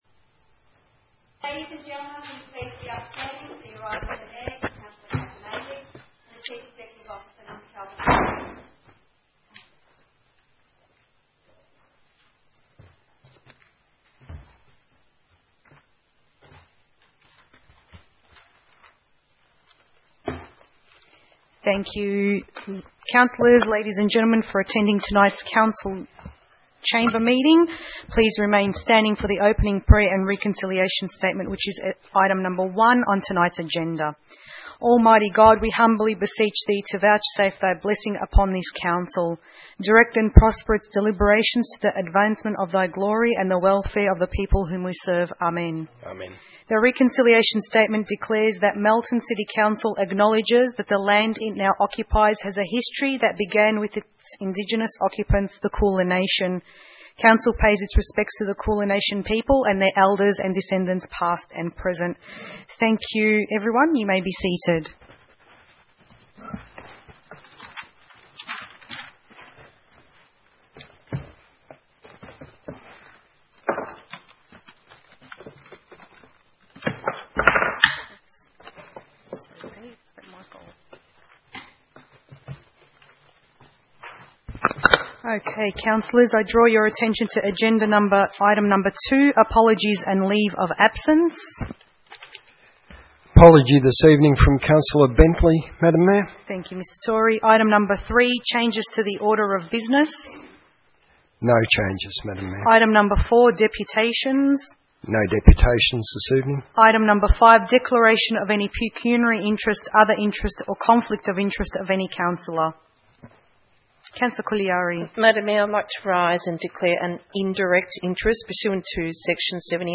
22 August 2016 Ordinary Council meeting